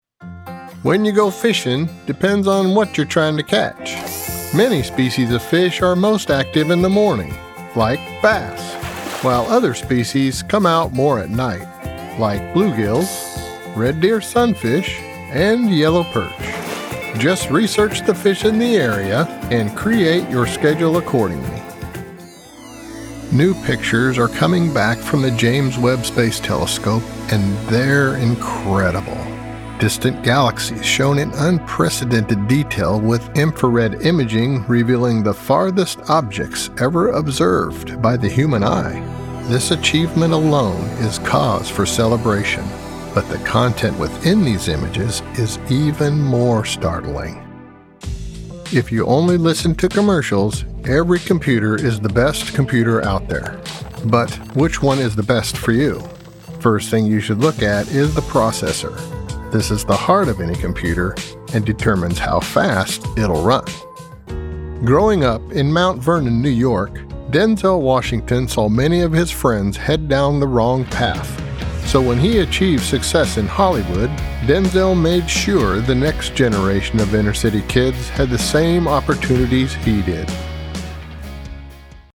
Unique rich character, warm genuine comfort
Unique. Rich. Warm. Comforting.
Narration VO | Demo
• Professional home studio & editing, based in Lincoln Nebraska
• Audio-Technica AT2020 Cardioid Condenser Microphone